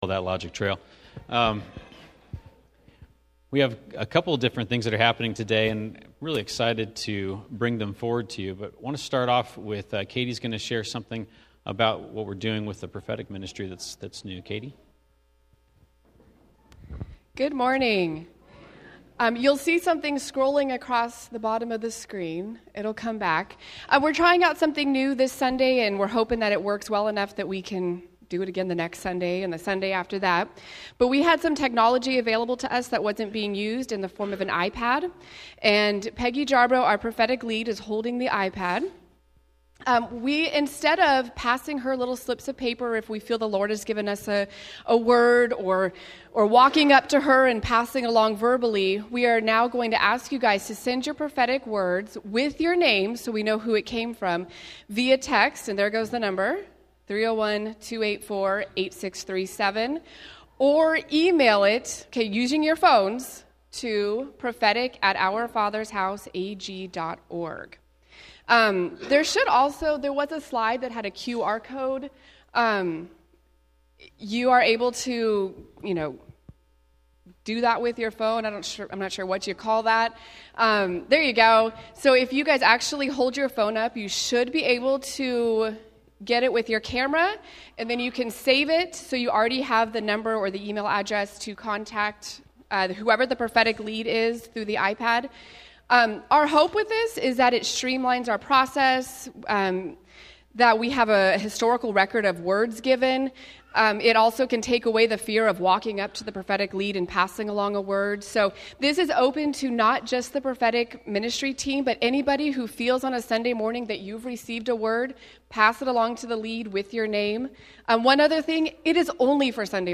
Although most of our Encounter services include extended times in musical praise and worship, Our Father's House chooses not to stream most of our musical segments.